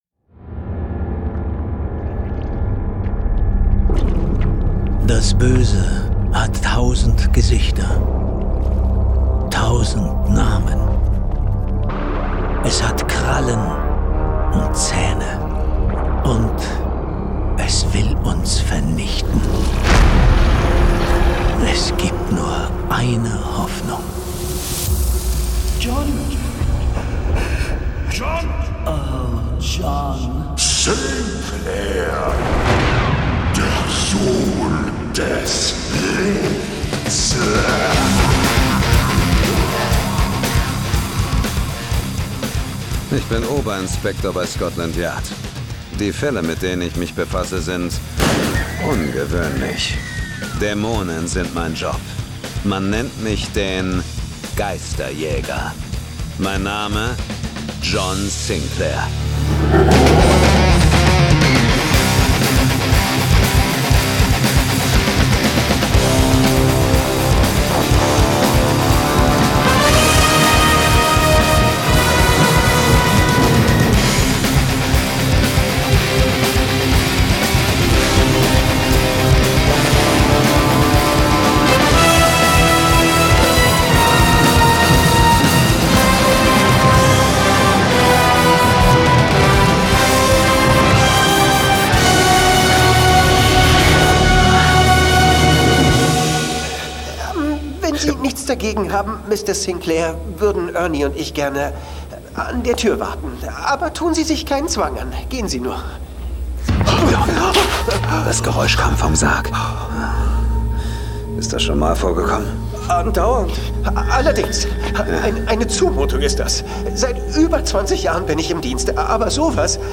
John Sinclair - Folge 185 Am Tisch des Henkers. Hörspiel.